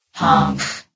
CitadelStationBot df15bbe0f0 [MIRROR] New & Fixed AI VOX Sound Files ( #6003 ) ...
honk.ogg